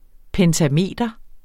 Udtale [ pεntaˈmeˀdʌ ]